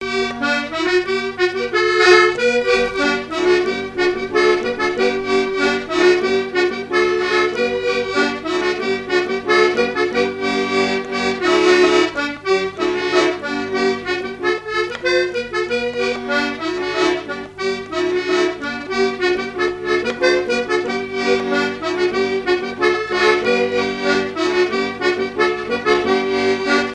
danse : pique dans la raize
danse : scottish
accordéon diatonique
Pièce musicale inédite